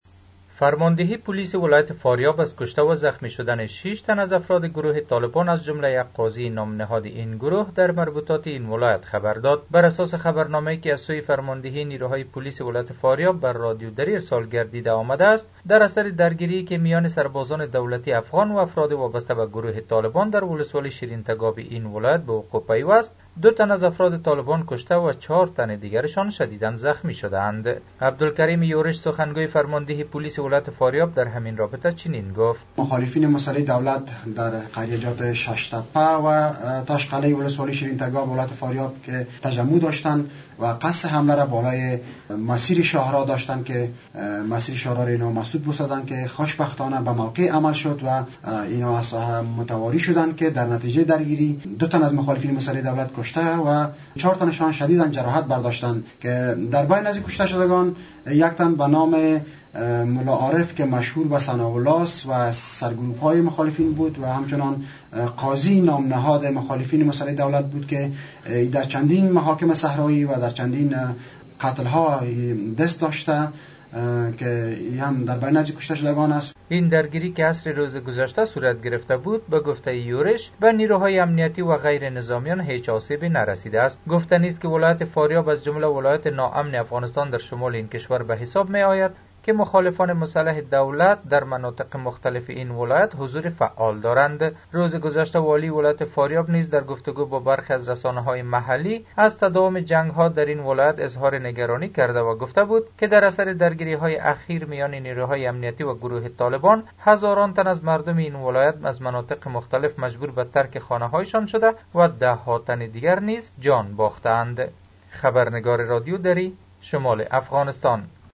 به گزارش رادیو دری از فاریاب، بر اساس اطلاع فرماندهی نیروهای پلیس فاریاب، در نتیجه درگیری بین نیروهای پلیس افغان با گروههای شورشی مسلح طالبان در ولسوالی شیرین تکاپ این ولایت 2 تن از افراد طالبان کشته و 6 نفر دیگر زخمی شدند.